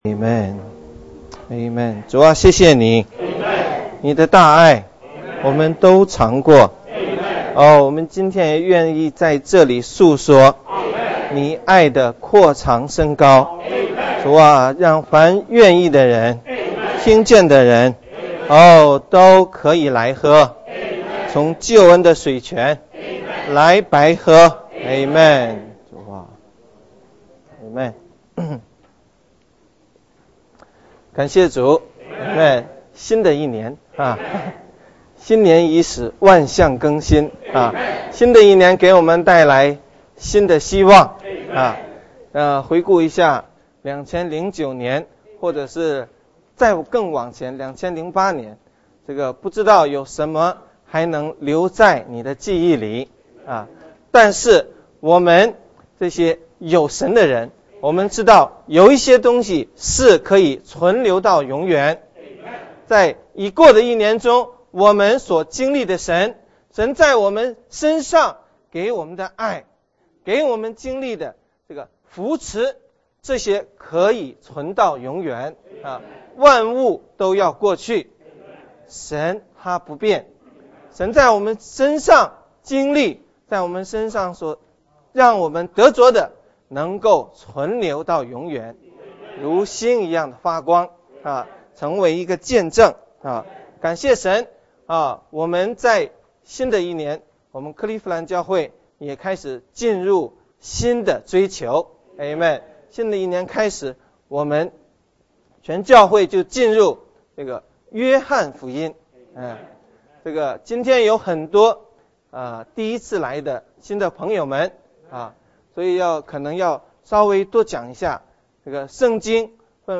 主日聚會